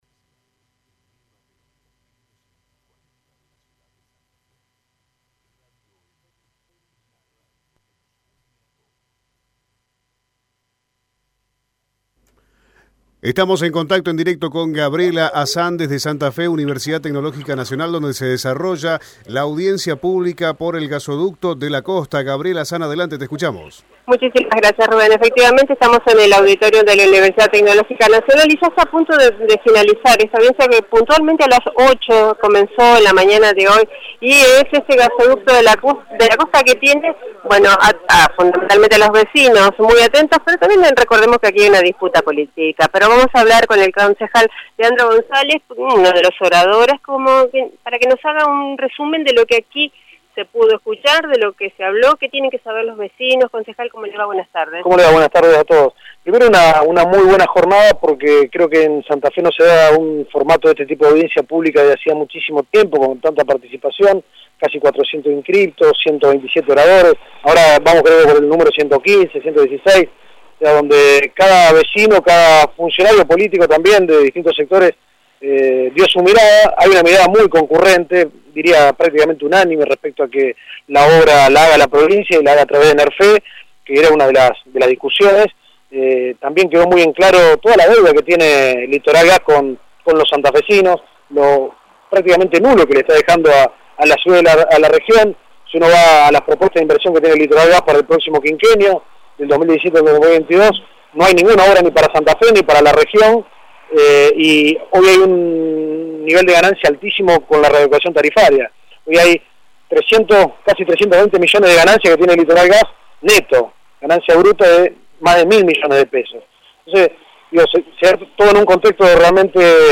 Así lo dijo el concejal Leandro González luego de salir de la audiencia pública que se realizó en la Universidad Tecnológica Nacional por el Gasoducto de la Costa. Esta gran obra será financiada por la provincia y llevará gas natural a las localidades de Arroyo Leyes, Colastiné, Rincón, entre otras. «Los vecinos de la costa tienen que saber que esto a corto plazo se va a realizar», expresó González en dialogo con Radio EME.